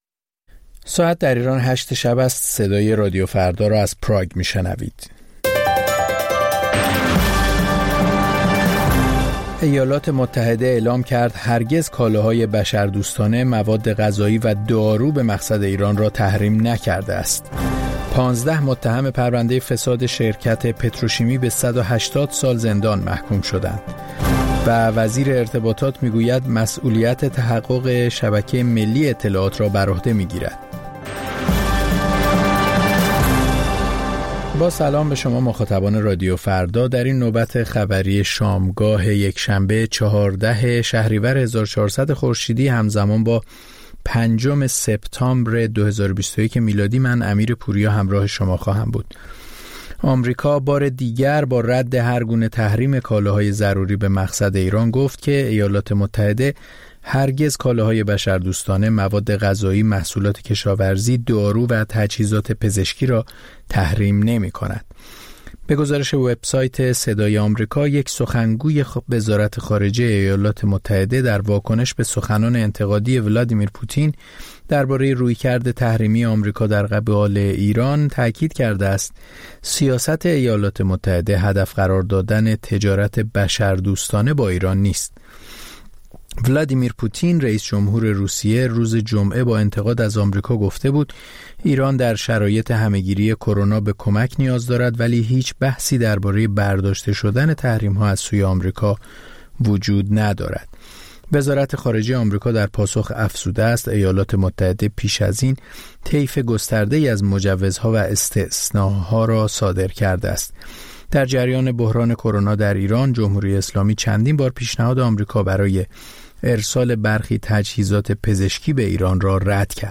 خبرها و گزارش‌ها ۲۰:۰۰